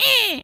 mouse_emote_10.wav